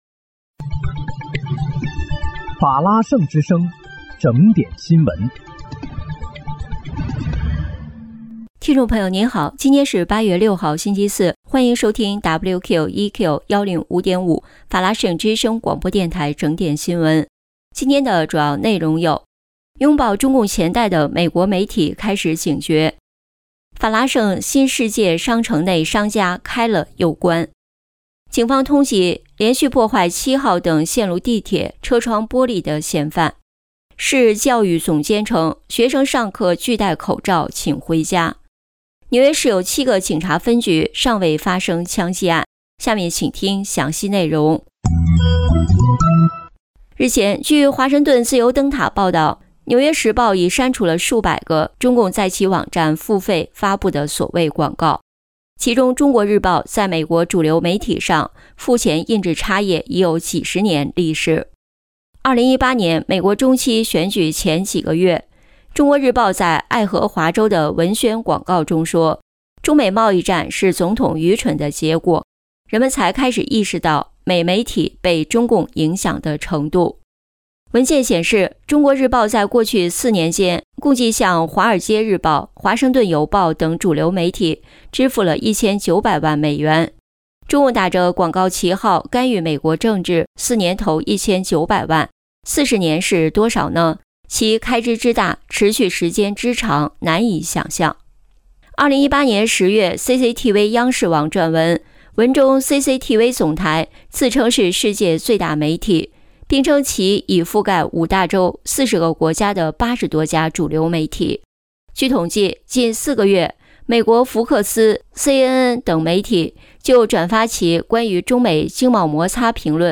8月6日（星期四）纽约整点新闻
听众朋友您好！今天是8月6号，星期四，欢迎收听WQEQ105.5法拉盛之声广播电台整点新闻。